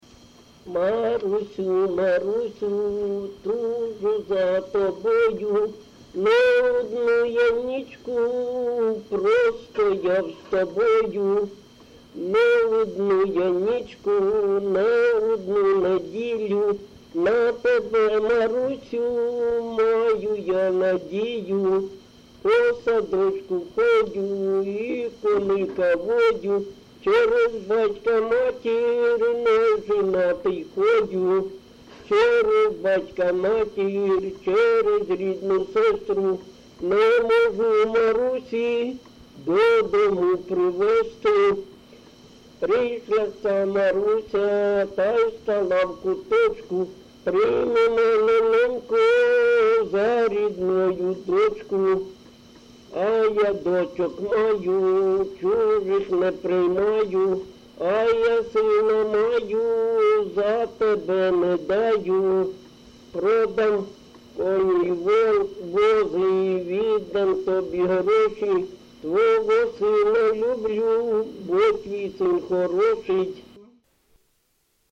ЖанрПісні з особистого та родинного життя, Романси
Місце записус. Клинове, Артемівський (Бахмутський) район, Донецька обл., Україна, Слобожанщина